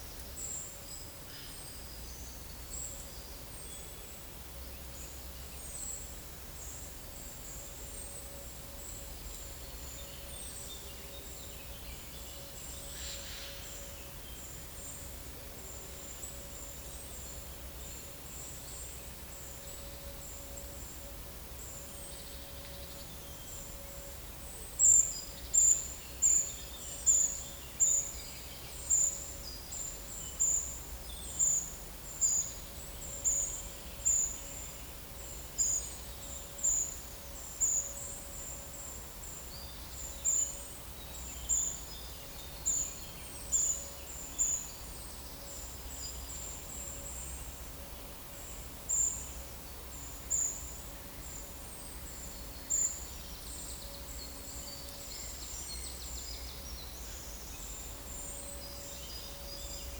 Monitor PAM
Certhia familiaris
Certhia brachydactyla